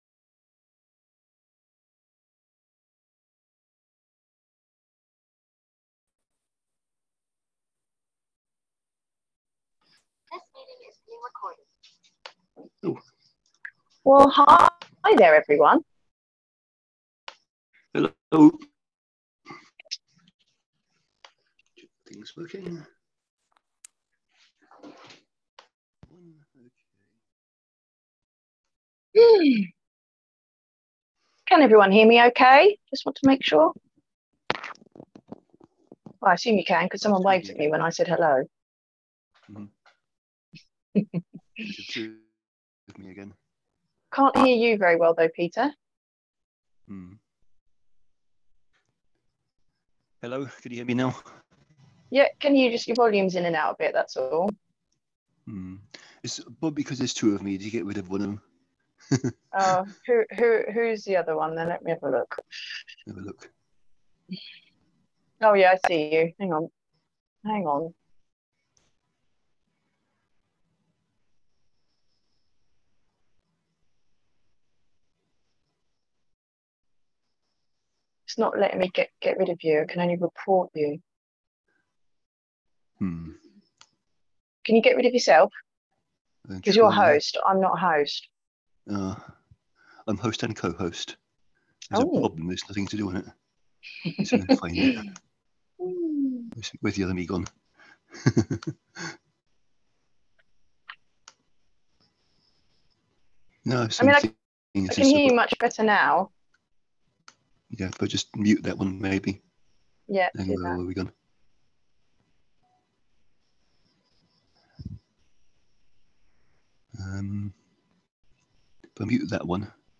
Call Replays